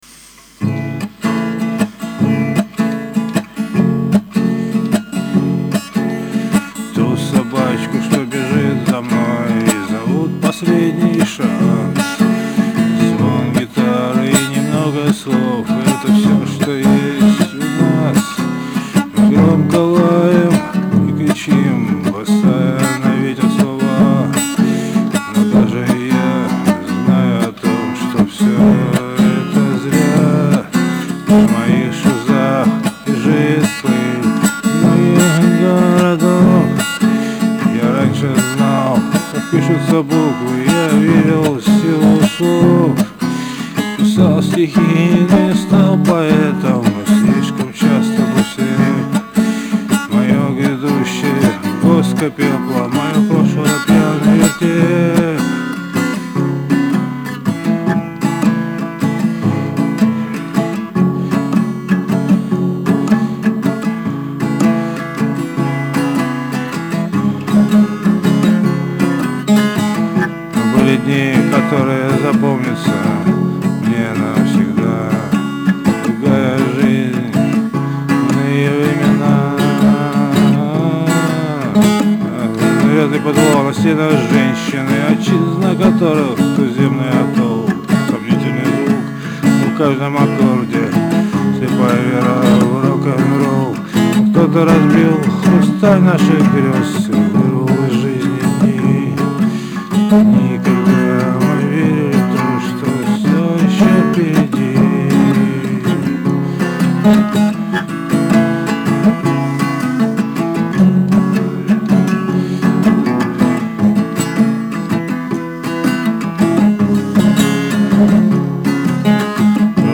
с**час выложу пробу звука